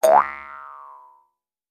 Звуки пружины
На этой странице собраны разнообразные звуки пружин: от резких щелчков до плавного скрипа.
Звук пружины в мультфильме